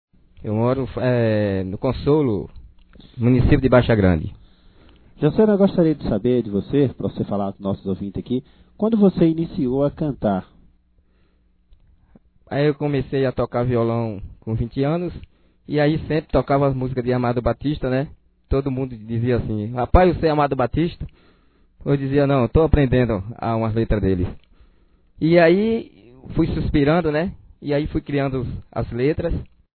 Em entrevista no programa A Voz da Comunidade que vaia ao ar todas as quarta-feira, ele responde as perguntas, ouça a seguir as reposta: